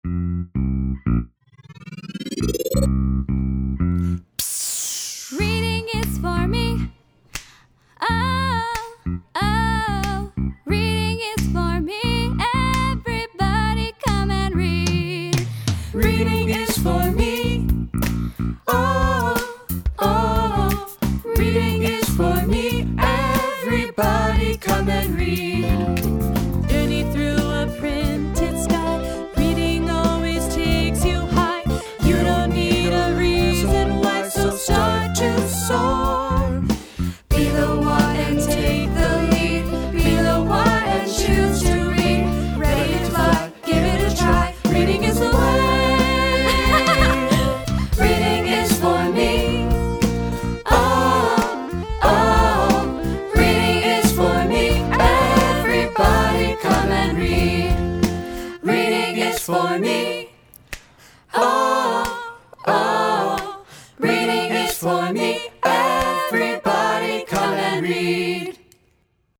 Today we had an ALL SCHOOL sing-a-long to celebrate March is reading month and March is music in our schools month!